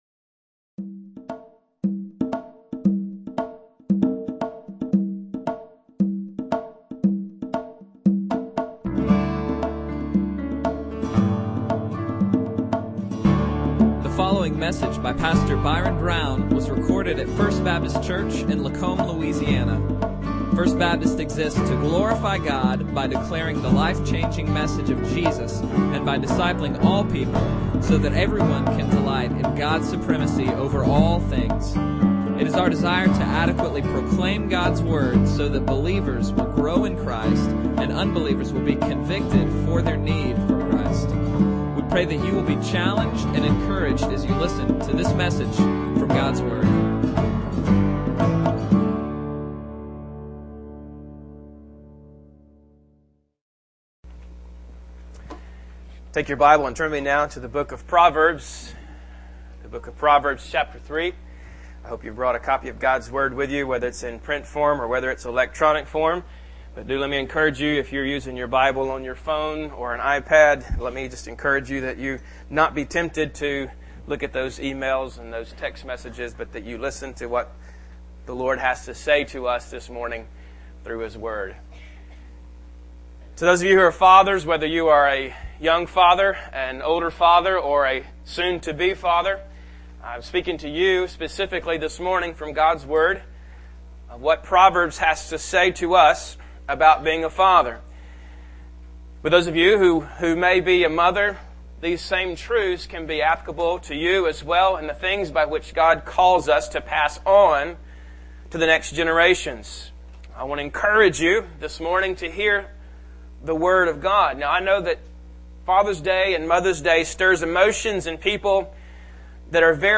Bible Text: Proverbs 3:1-12 | Preacher: